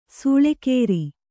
♪ sūḷekēri